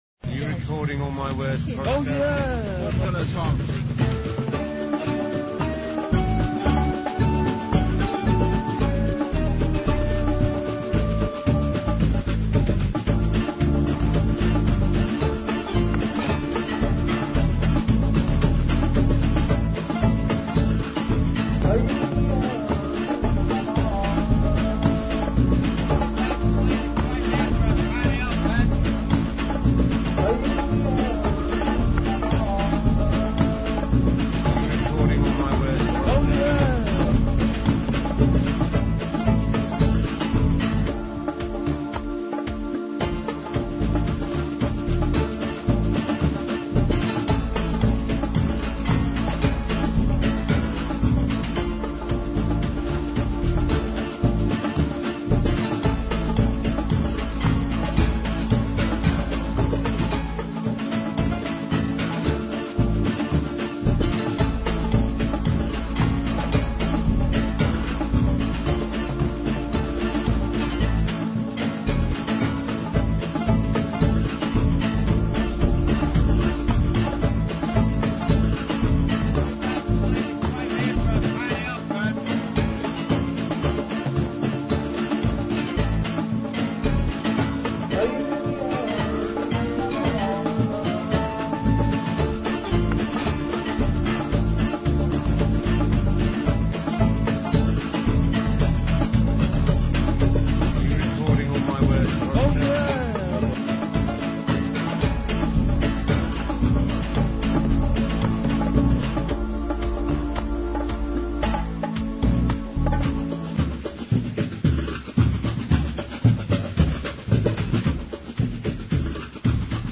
A composition centering around a sound sample of the prospective Mayoral candidate turning away from his interrogating public, to ask me a question.
Lo res MONO: -522Kb
After the "Mayor to be" left the scene with his entourage civilisé, I recorded a municipal drum band further up the street, and some sounds from Surrey St. Market. I used the samples, and the inspiration of meeting him to create a piece of music.